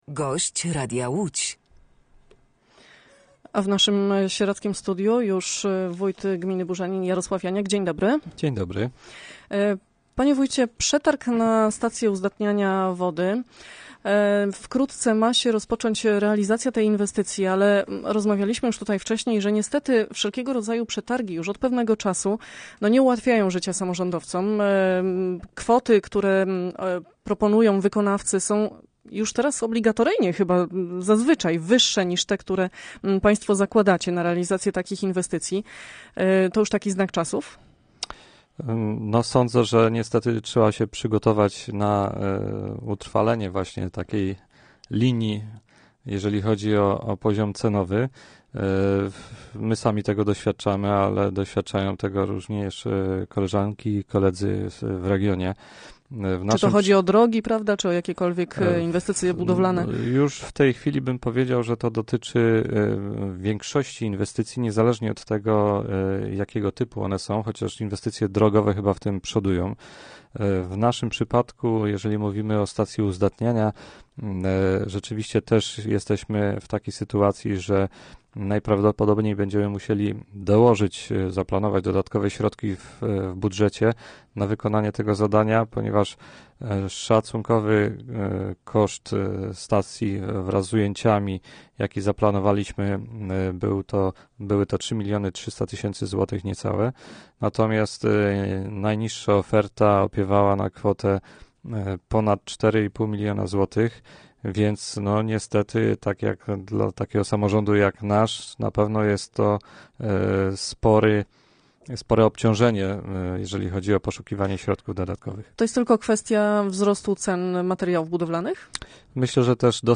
Gościem Radia Łódź Nad Wartą był wójt gminy Burzenin, Jarosław Janiak.